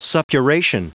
Prononciation du mot suppuration en anglais (fichier audio)